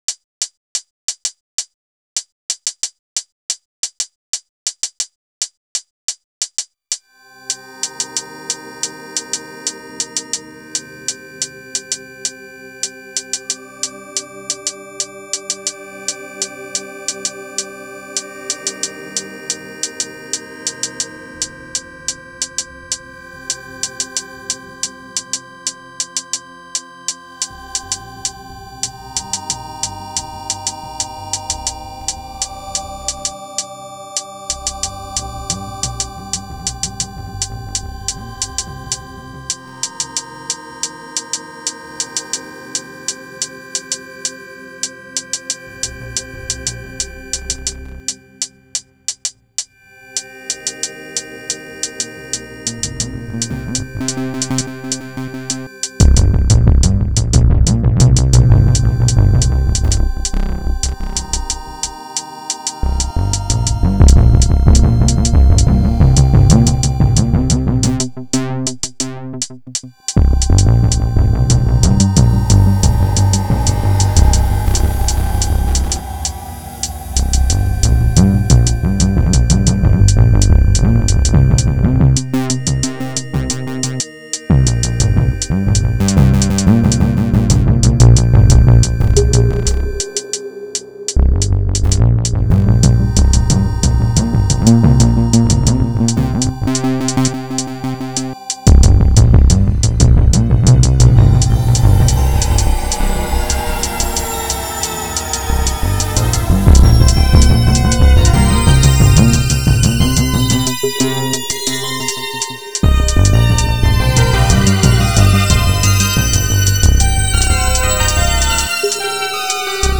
Categorised in: dark, rhythmic, urgent